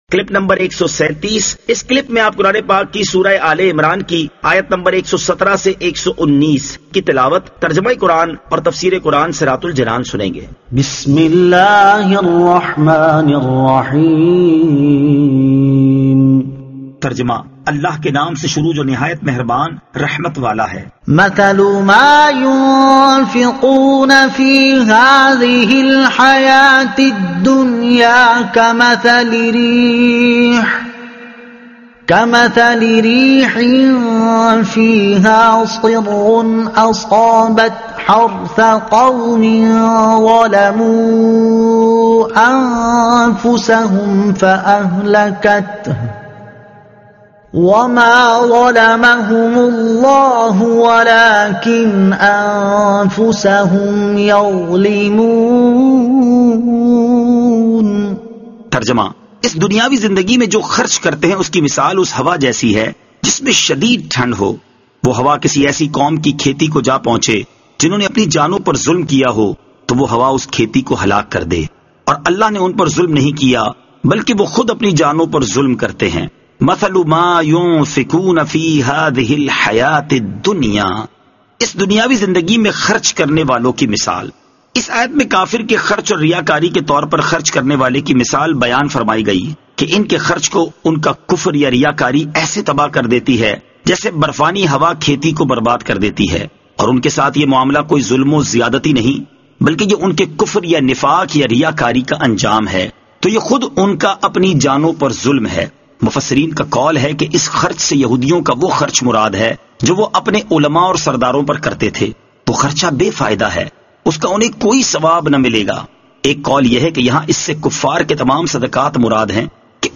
Surah Aal-e-Imran Ayat 117 To 119 Tilawat , Tarjuma , Tafseer